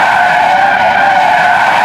AsphaltSkid2.wav